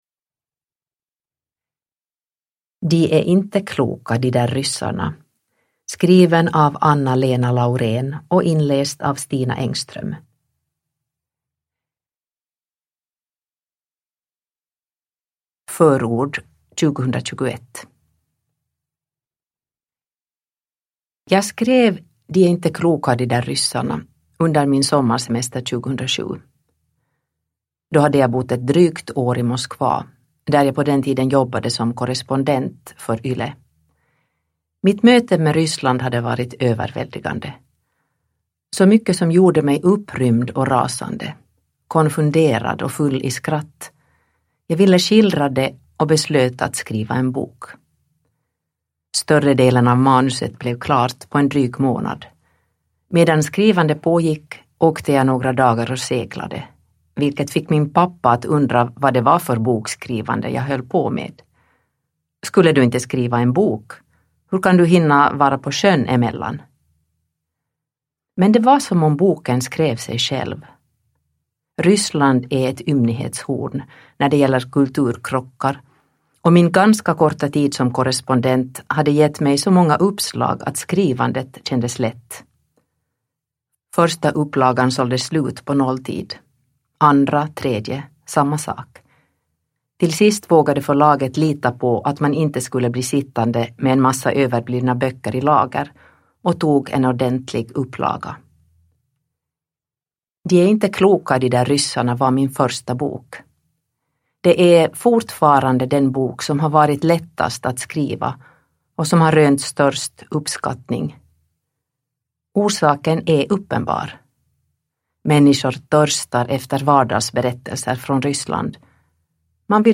De är inte kloka, de där ryssarna – Ljudbok – Laddas ner